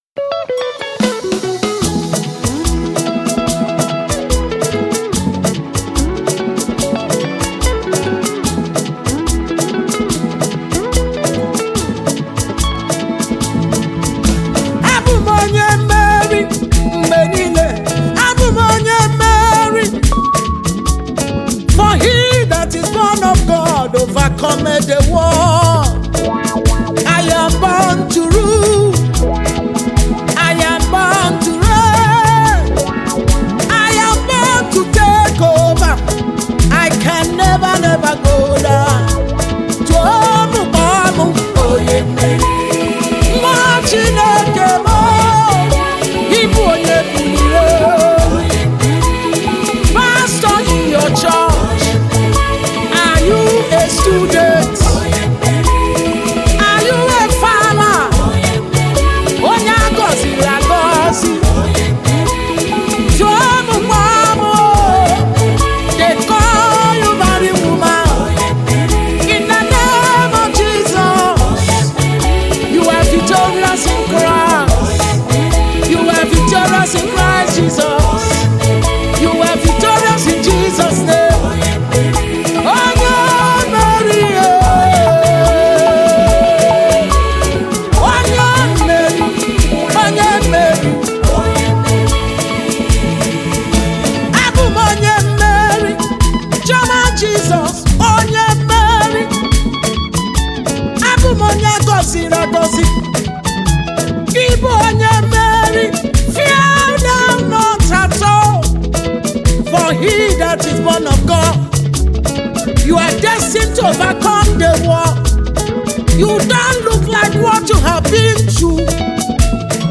which incredible spirit filled tracks.